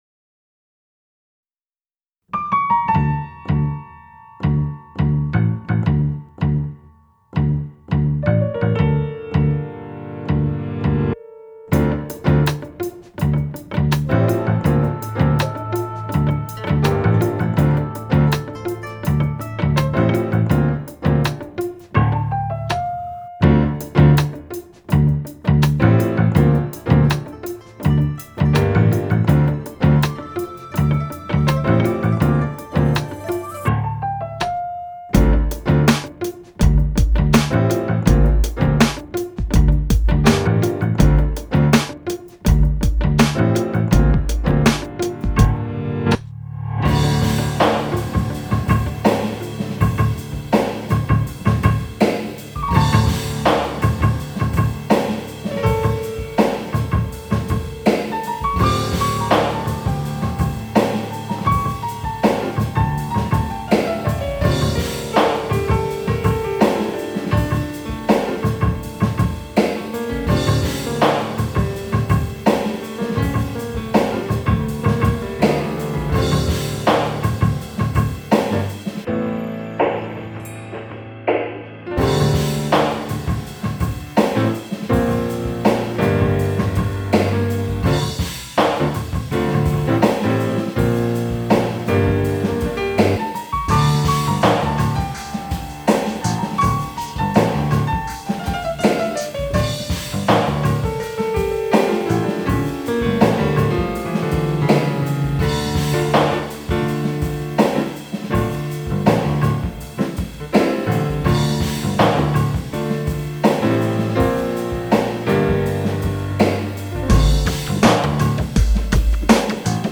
Das nachfolgende Klangexperiment wurde im Mehrspurverfahren überwiegend mit dem Flügel aufgenommen. Die Klänge wurden durch das Abstoppen der Saiten stark verändert, wodurch sich ein präziser "Bass" ergibt. Auch die gitarrenähnlichen "muted" Sounds wurden so erzeugt, einzeln aufgenommen und im Arrangement wieder zusammengeführt.
Kangexperiment Flügel (MP3)
Klangexperiment_TA.mp3